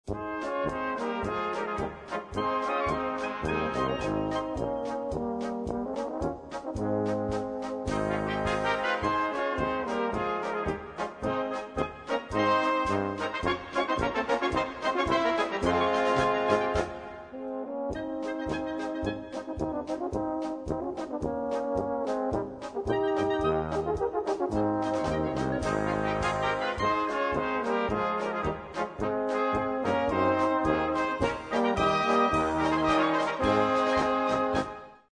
POLKA